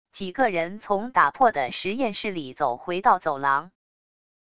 几个人从打破的实验室里走回到走廊wav音频